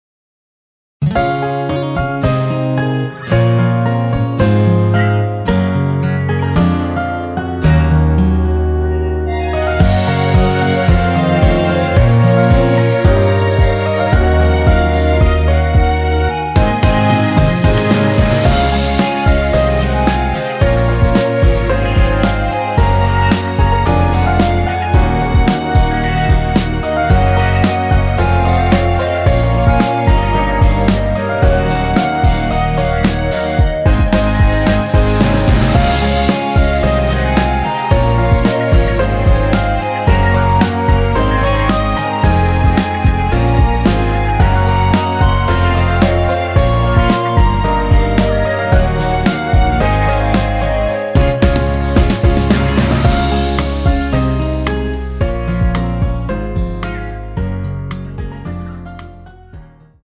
원키에서(-1)내린 멜로디 포함된 MR입니다.
앞부분30초, 뒷부분30초씩 편집해서 올려 드리고 있습니다.
중간에 음이 끈어지고 다시 나오는 이유는
곡명 옆 (-1)은 반음 내림, (+1)은 반음 올림 입니다.
(멜로디 MR)은 가이드 멜로디가 포함된 MR 입니다.